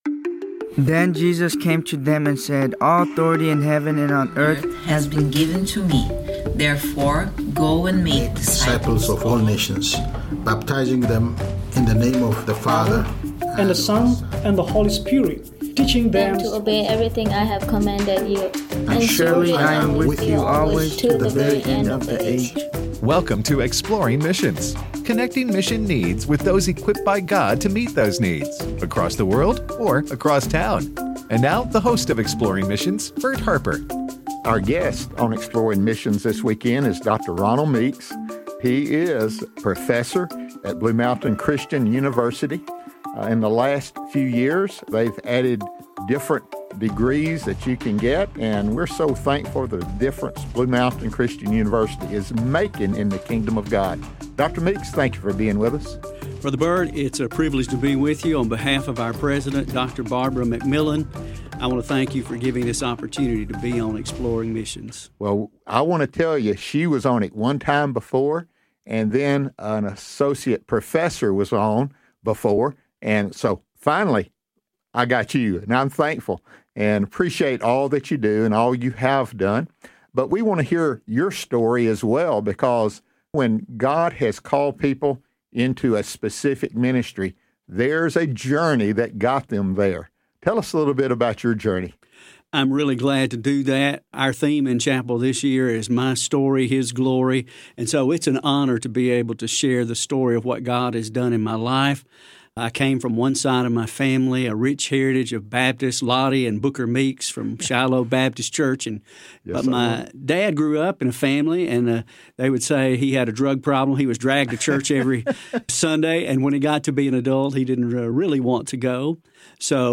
The Journey: A Conversation